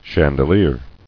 [chan·de·lier]